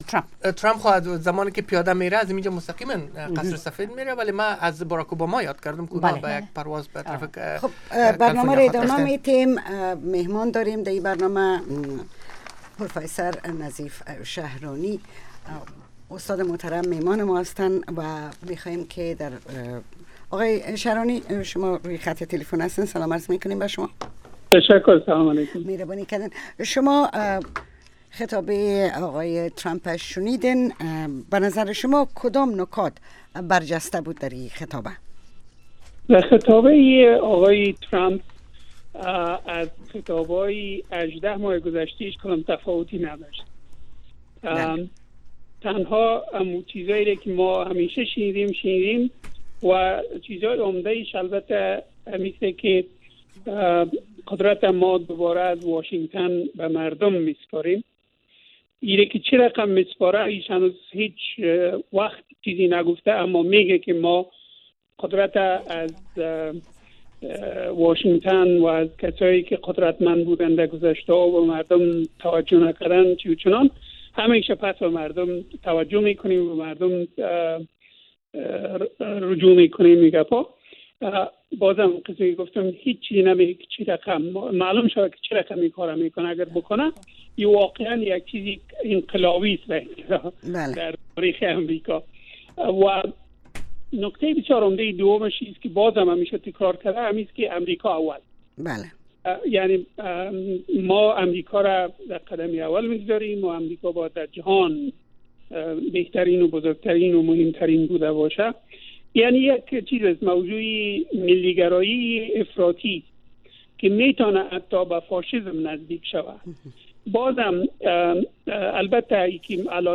برنامه گفت و شنود/خبری اتری - گفتمان مشترک شما با آگاهان، مقام ها و کارشناس ها.